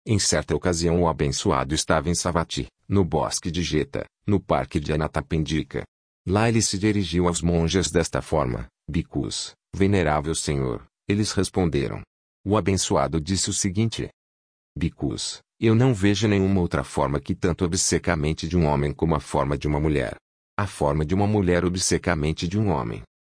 Yes, Amazon Polly, where we have our voices from, also offers voices in Brazilian Portuguese: